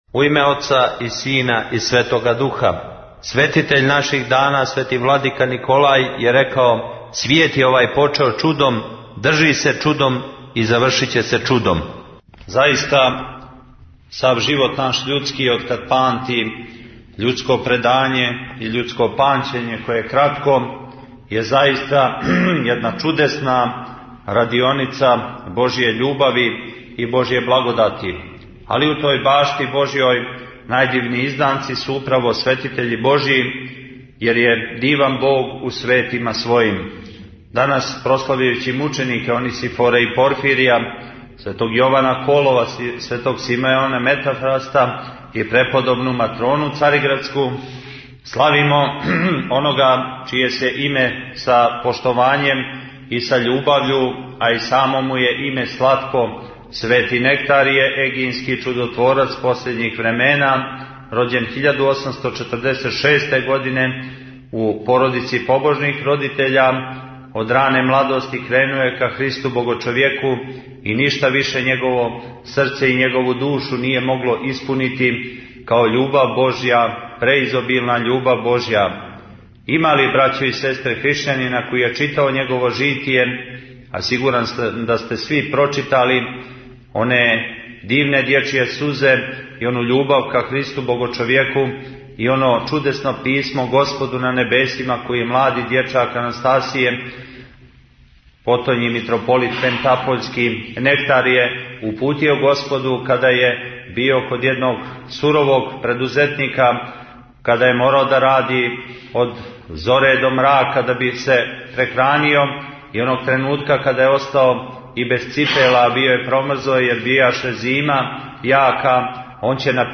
Бесједе
У цркви Вазнесења Господњег у Подгорици данас је служена Света Литургија.